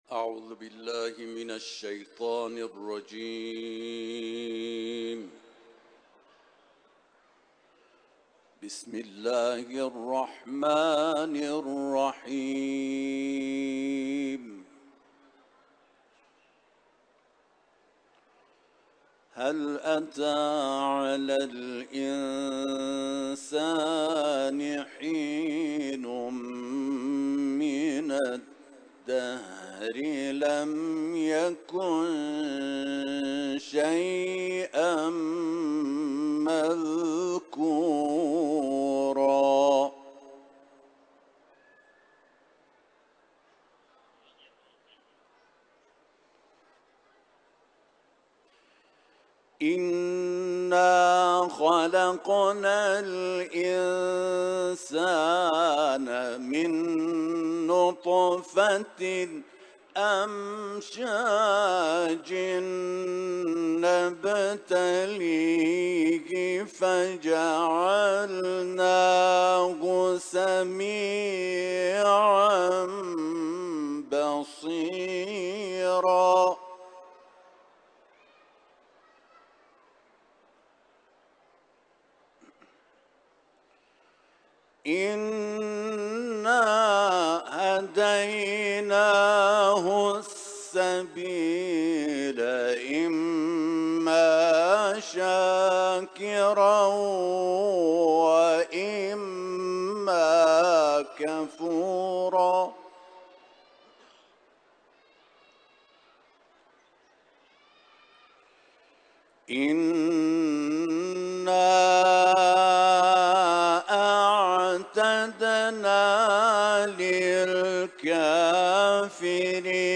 تلاوت‌ ، سوره انسان ، حرم مطهر رضوی